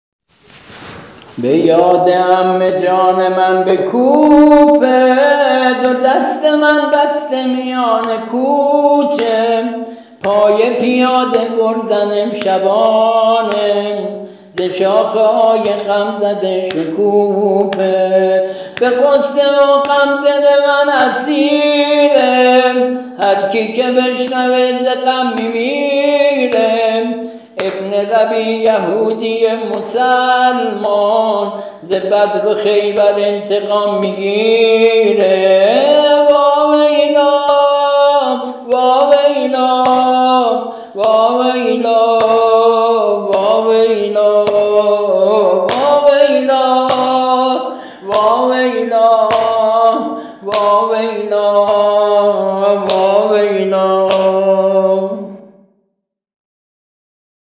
◾نوحه‌ی سینه‌زنی